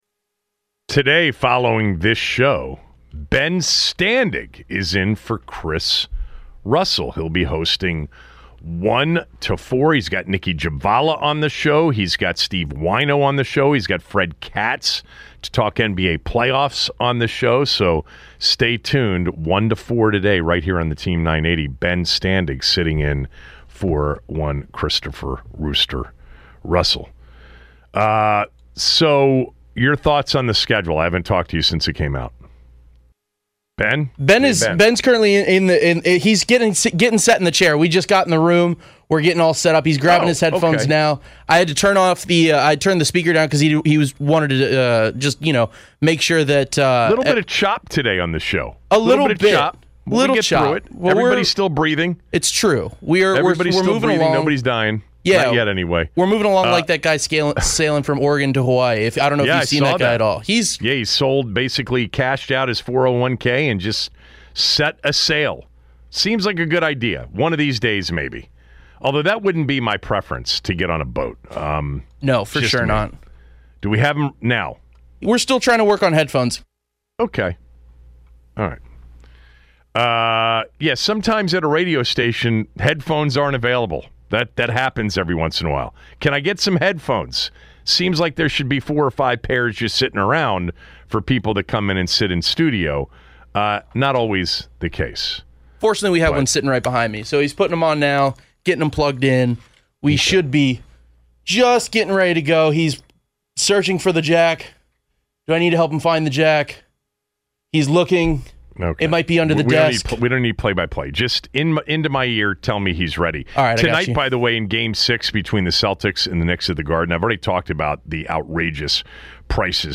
callers recap the Capitals season and whether it was a success or not.